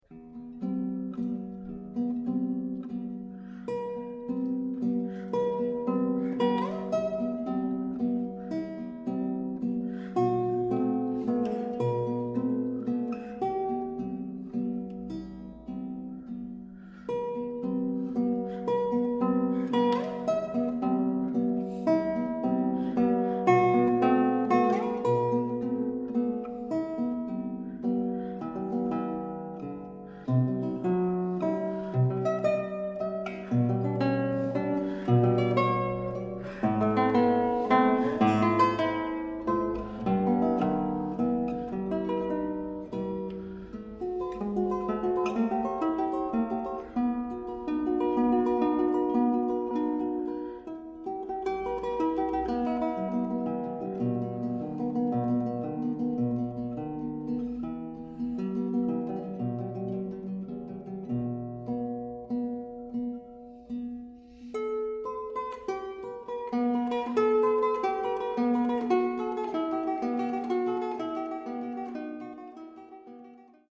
Skladby pro osmistrunnou kytaru
"Dřevěný kostelík", Blansko 2010